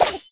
Skeleton_elite_atk_01.wav